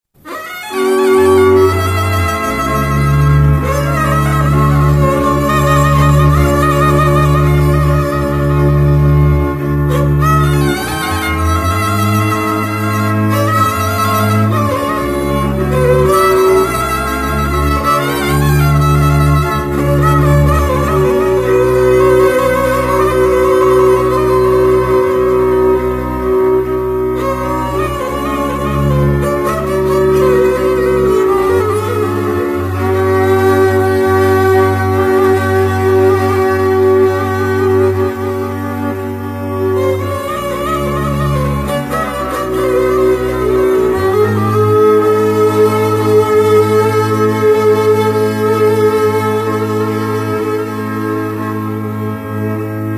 Dallampélda: Hangszeres felvétel
Erdély - Szolnok-Doboka vm. - Ördöngösfüzes
hegedű
kontra
bőgő
Műfaj: Katonakísérő
Stílus: 3. Pszalmodizáló stílusú dallamok
Kadencia: 4 (b3) VII 1